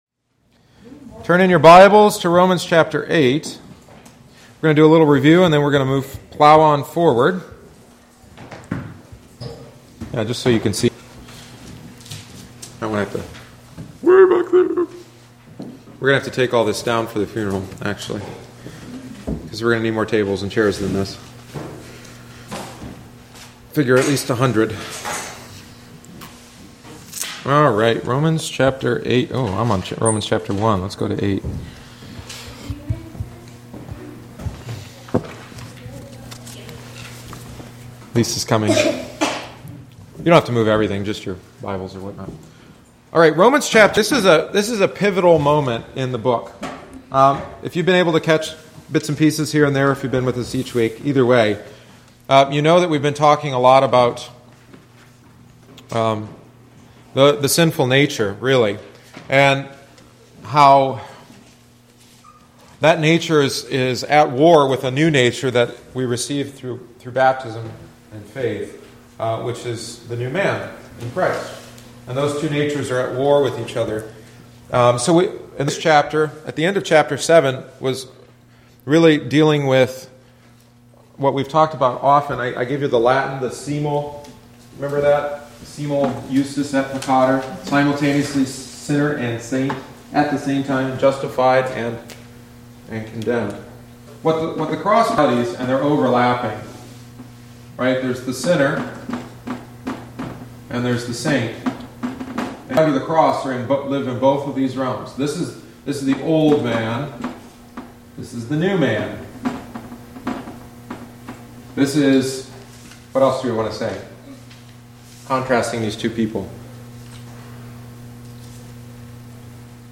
The following is the nineteenth week’s lesson. There are only two stances a person can take regarding Christ.